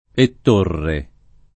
ett1rre] cogn. — con l’-o- aperto della var. poet. di Ettore, o con un -o- chiuso dovuto all’attraz. di torre: preferenze diverse nelle diverse famiglie — cfr. D’Ettorre